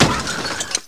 Crash.mp3